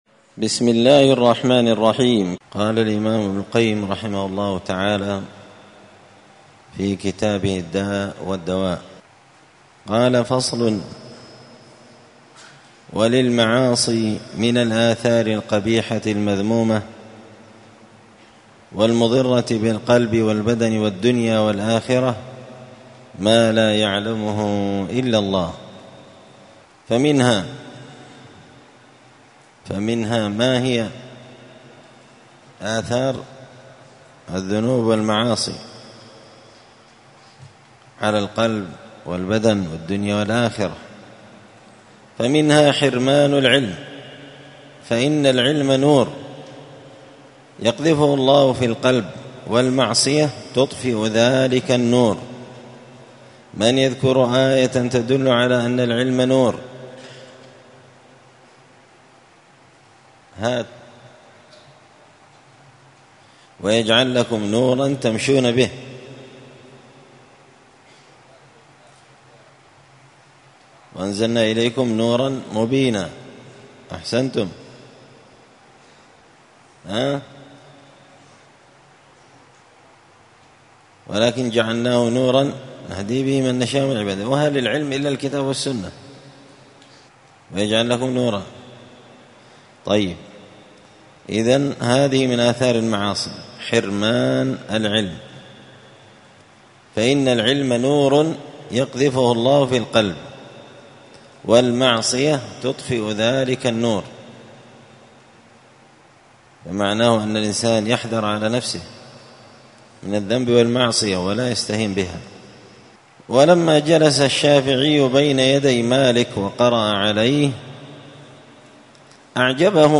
مسجد الفرقان قشن_المهرة_اليمن 📌الدروس الأسبوعية